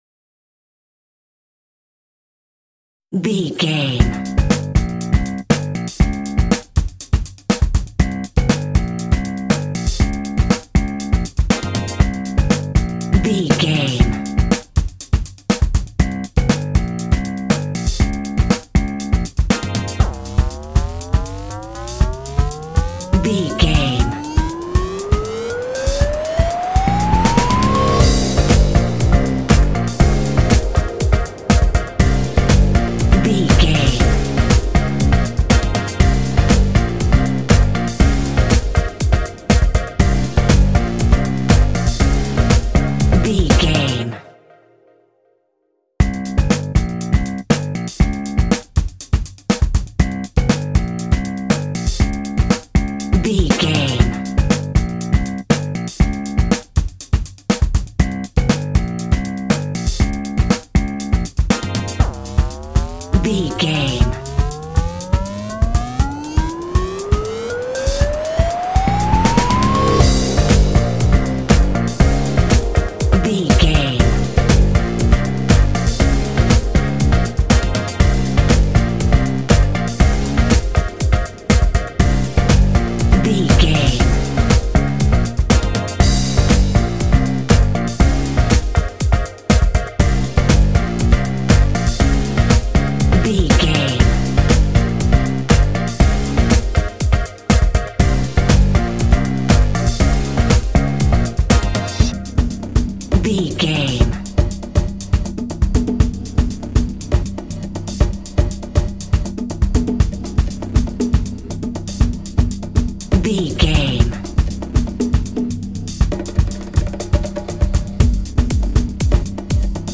Nu Disco Funk.
Aeolian/Minor
funky
groovy
uplifting
driving
energetic
bass guitar
synthesiser
electric piano
drums
drum machine
funky house
upbeat
funky guitar
clavinet
synth bass
horns